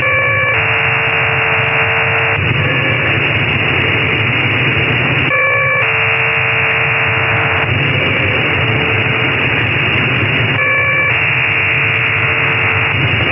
Burst system also known as PRC 30 after its country of origin, the People’s Republic of China.
Modulation: PSK‑4. 4 Tone preamble, 4 x 60 Bd. Pilot tone at 450 Hz
Bandwidth: 2.5 kHz
Baud rate: 30 x 60 Bd